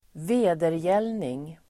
Uttal: [²v'e:derjel:ning]